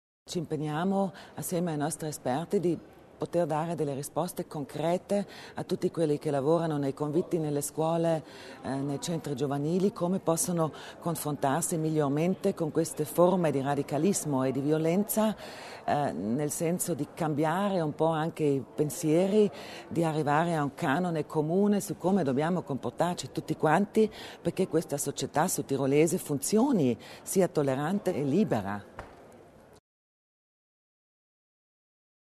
L'Assessore Kasslatter Mur sull'impegno della Provincia contro gli estremismi
Scuola / Cultura | 27.11.2009 | 15:04 Interessante convegno all’Eurac sull’estremismo di destra I temi dell’estremismo di destra e della violenza sono stati al centro di un convegno organizzato oggi (27 novembre) all’Eurac dall’Intendenza scolastica e dall’Istituto pedagogico di lingua tedesca. L'assessora Kasslatter Mur nel corso del convegno sull'estremismo di destra Nel corso del suo intervento al convegno l’assessore alla scuola e cultura Sabina Kasslatter Mur ha ribadito il ruolo fondamentale che la politica può svolgere nei confronti dell’estremismo di destra e della violenza soprattutto nel campo della prevenzione.